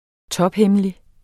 Udtale [ ˈtʌbˈhεməli ]